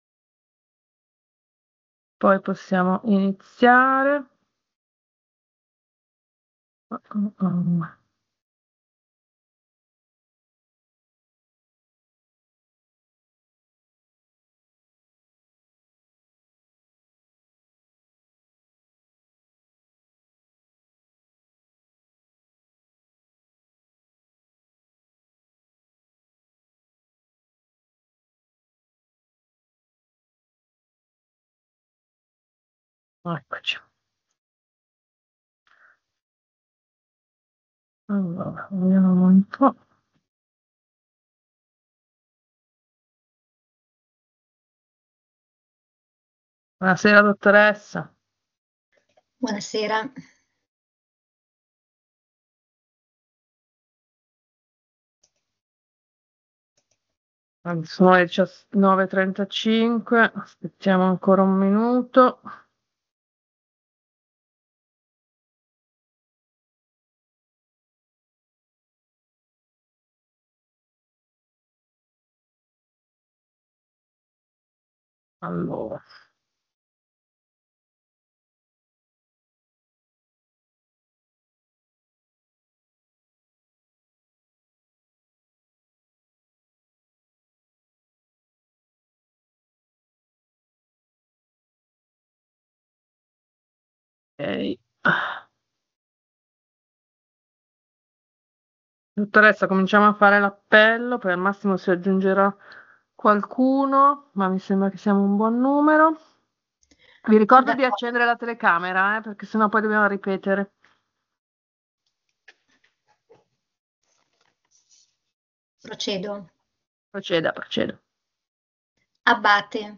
16 dicembre 2024 Il Consiglio di Municipio 8 è convocato in modalità video conferenza su piattaforma Teams lunedì 16 dicembre 2024 dalle ore 19:30 alle ore 21:00 Interventi dei Cittadini...